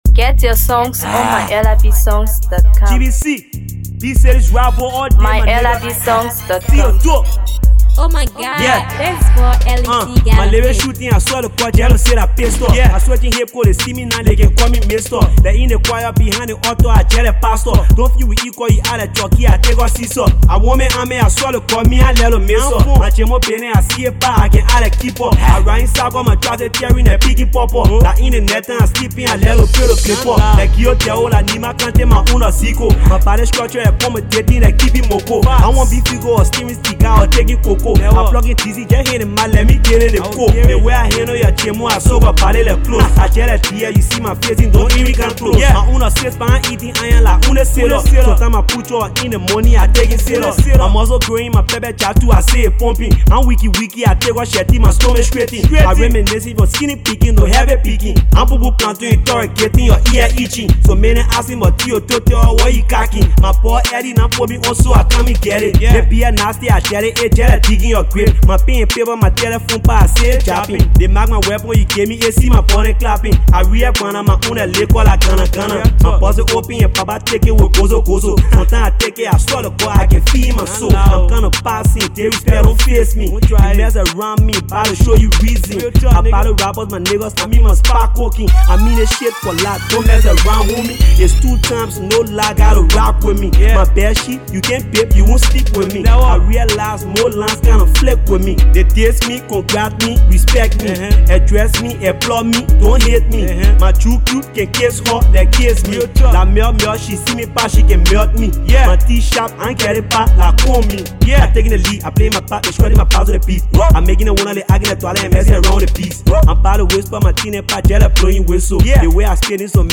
Hip HopHipco
Liberian sensational Hipco rap artist
” a hard-hitting anthem that reflects the hunger
raw street energy with sharp lyrical delivery
With a gripping beat and commanding flow